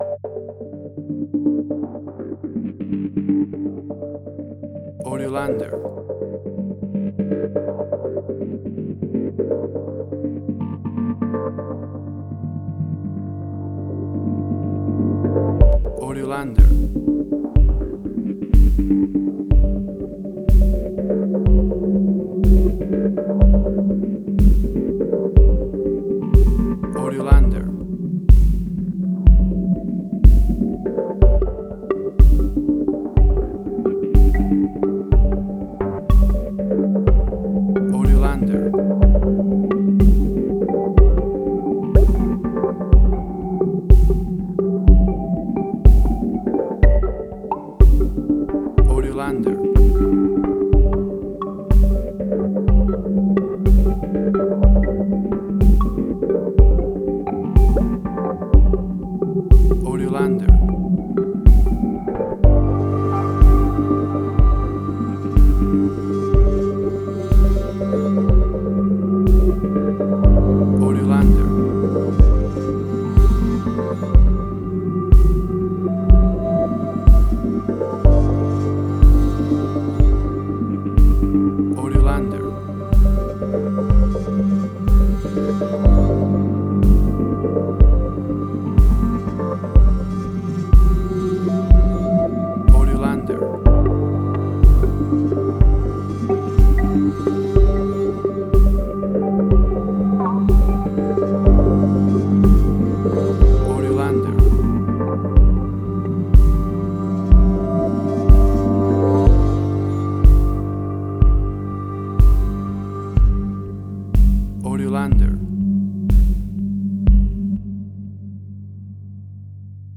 Suspense, Drama, Quirky, Emotional.
Tempo (BPM): 61